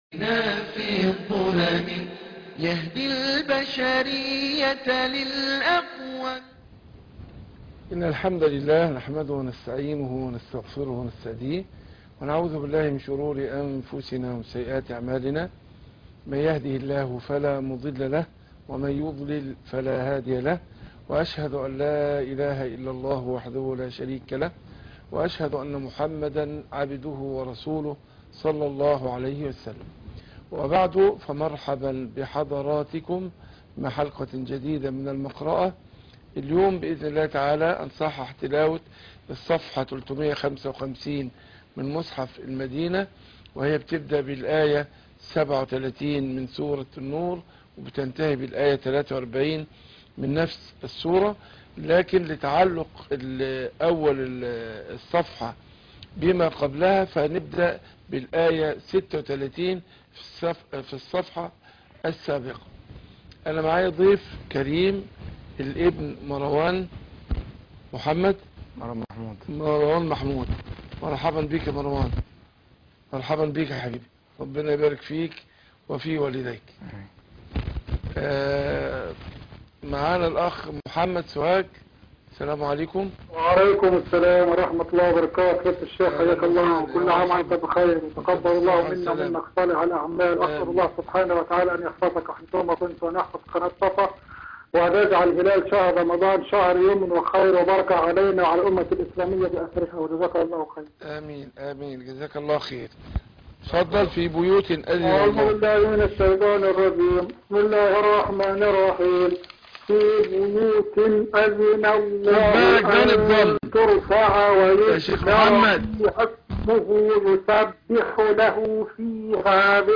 المقرأة - سورة النور ص 355